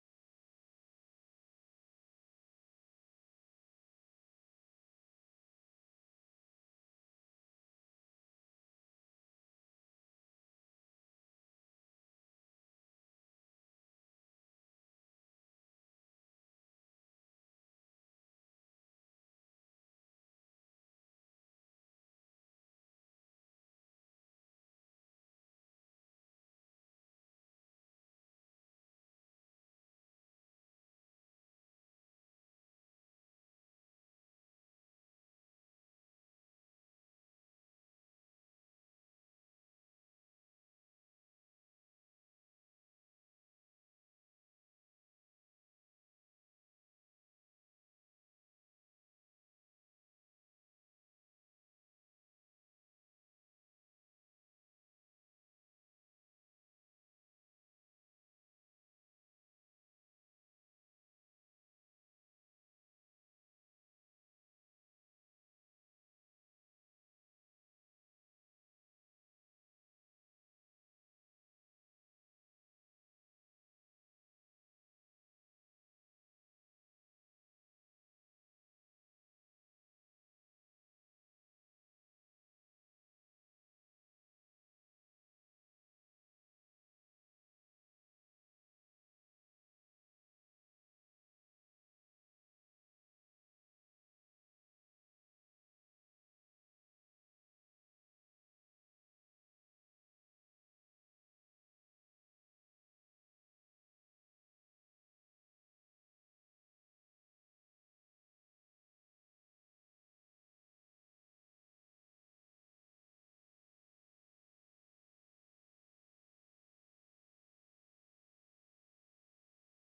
Слушайте Кошачьи уши - аудиосказка Бажова П. Сказка о временах крестьянского восстания под предводительством Емельяна Пугачева.